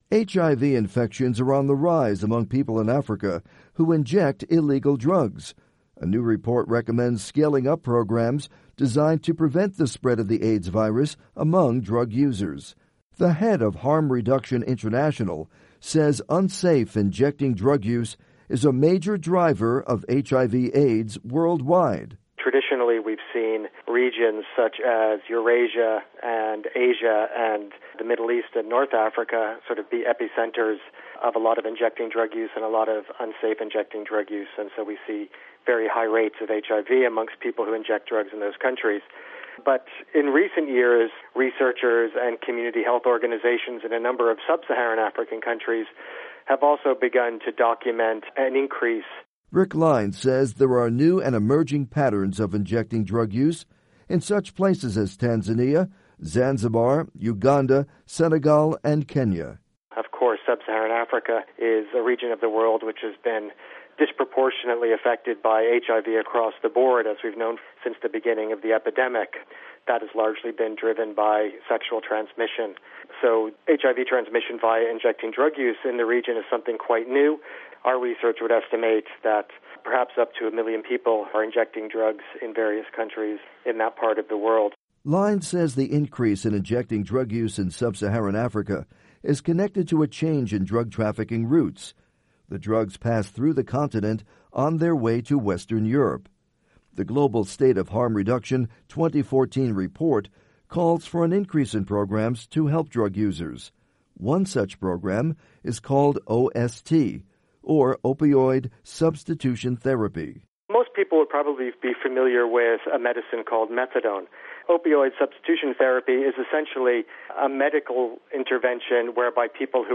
report on HIV and injecting drug use